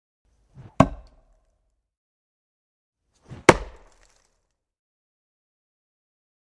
Звуки томагавка
Кидаем в дерево и втыкается